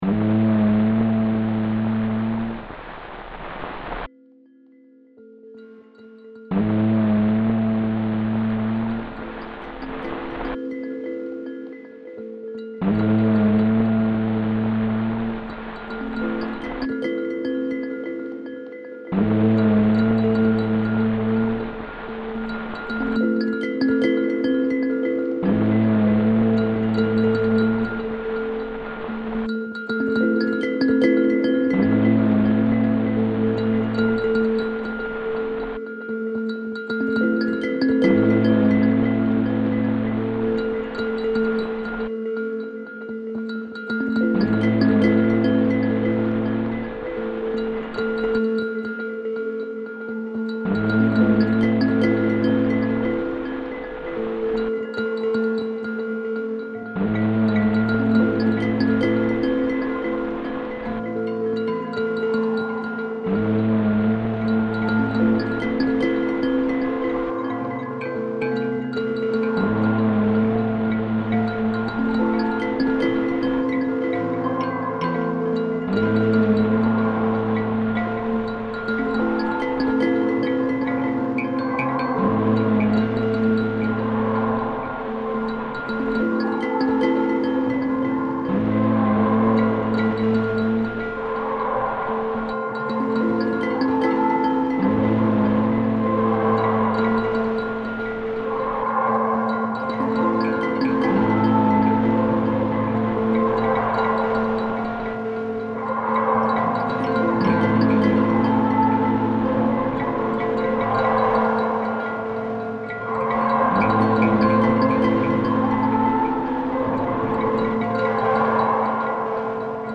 This piece is built around a short fragment of an Igbo wind instrument recording taken from the Pitt Rivers Museum sound collections. The original recording was made on wax cylinder by anthropologist Northcote Thomas in Nigeria between 1909 and 1915.
Rather than treating the field recording as documentary material, I focused on its breath, tone, and repetition. I isolated a small loop from the wind instrument and gently pitch-shifted it, allowing the sound to move away from its original temporal context and into something more meditative and suspended. The looping process emphasises continuity and endurance, while also acknowledging the artificiality of repetition imposed by modern technology.